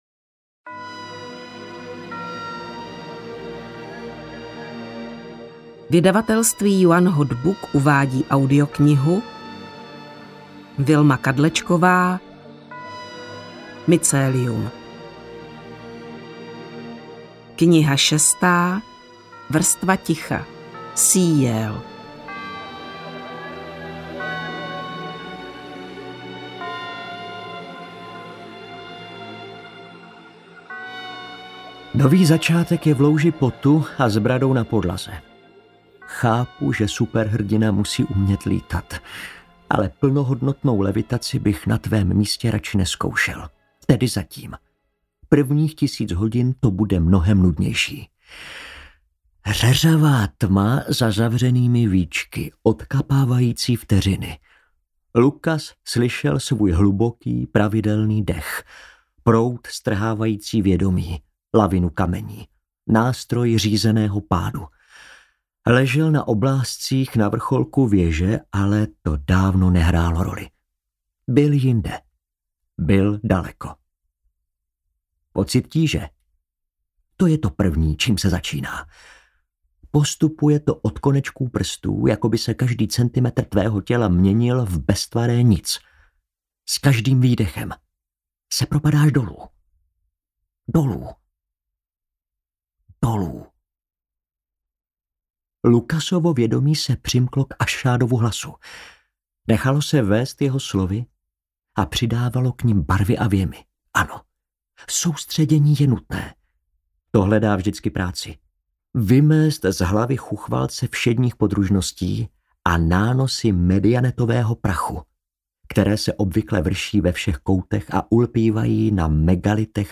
Mycelium VI: Vrstva ticha audiokniha
Ukázka z knihy